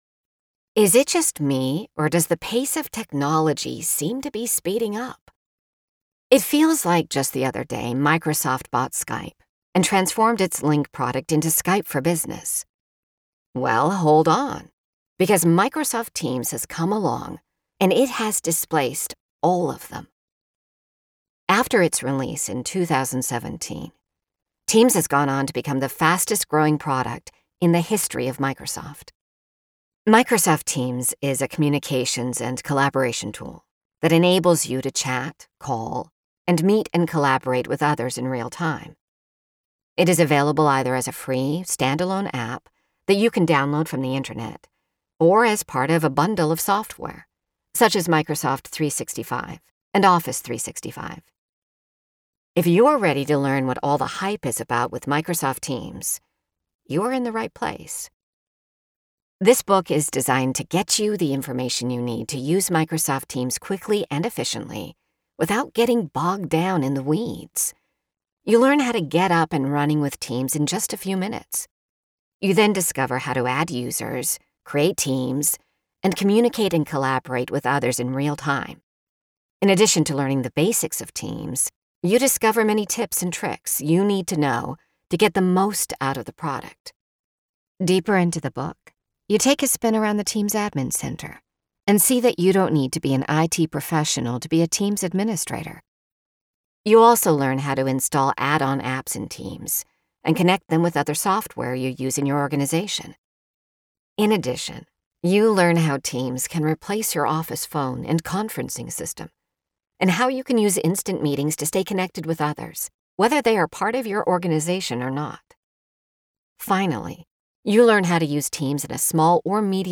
AUDIOBOOK  SAMPLES
non-fiction-business-demo.mp3